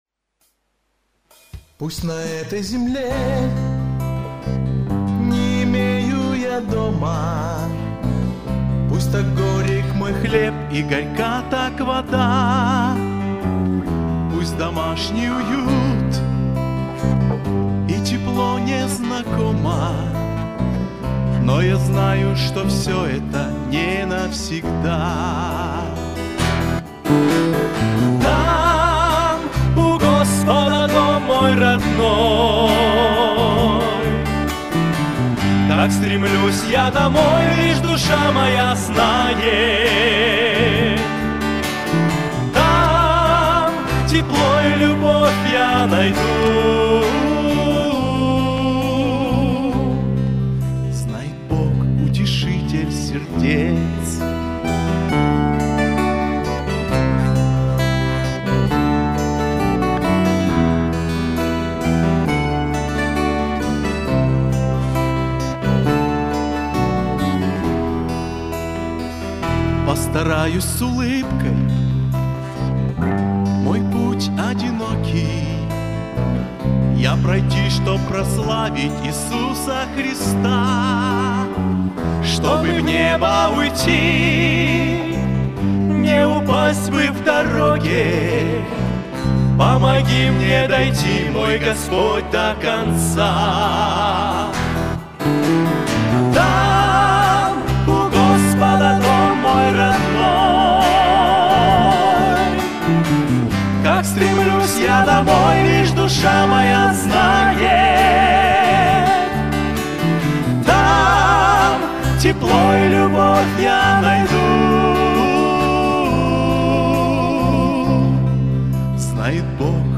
Богослужение 28.09.2024
Там у Господа - Братья (Пение)[